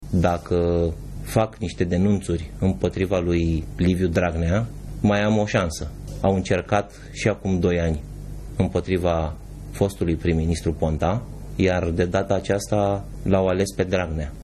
În timp ce polițiștii îl caută de o săptămână, fostul deputat Sebastian Ghiță a apărut, într-o înregistrare difuzată de România TV.